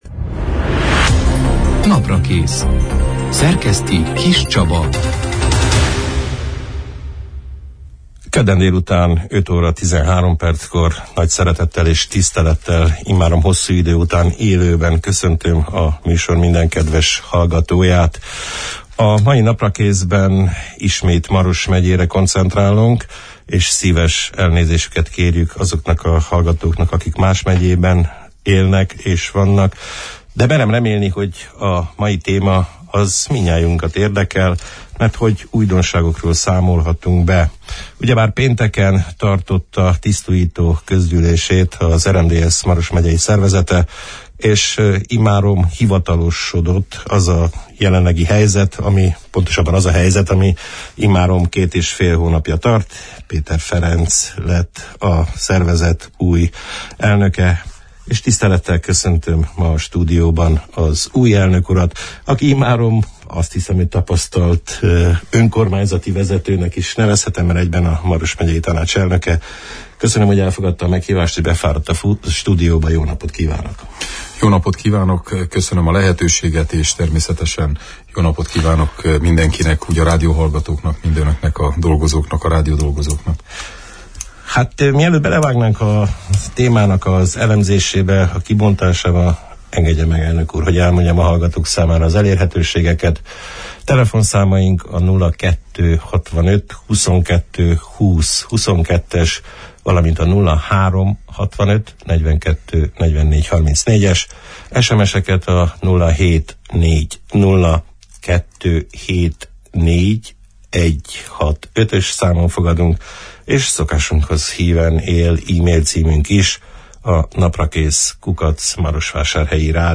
A belső feszültségek oldását célzó elképzeléseiről, a csapatmunka fontosságáról, a szervezet megerősítéséről, a szervezeti struktúra megváltoztatásáról, a megyei önkormányzat aktuális feladatairól beszélgettünk a február 28 – án, kedden elhangzott Naprakész műsorban Péter Ferenccel, az RMDSZ Maros megyei szervezetének új elnökével, a Maros Megyei Tanács vezetőjével.